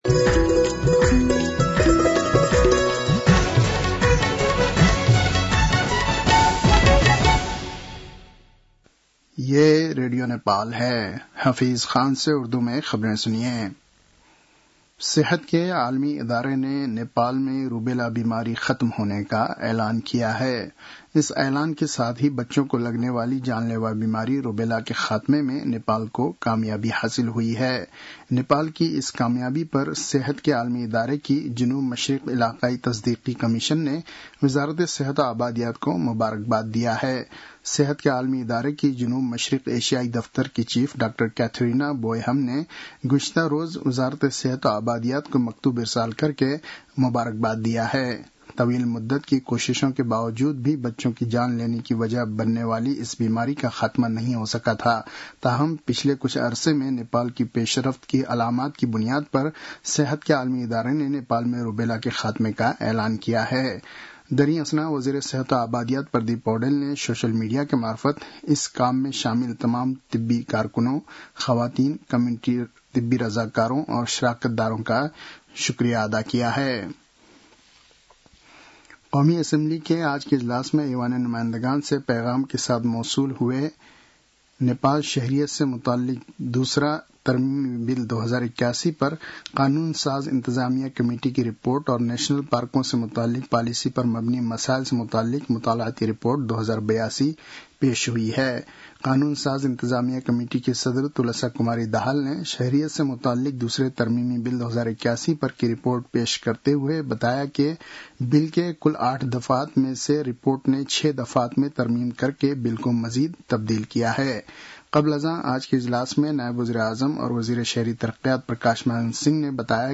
उर्दु भाषामा समाचार : ३ भदौ , २०८२